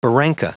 Prononciation du mot barranca en anglais (fichier audio)
Prononciation du mot : barranca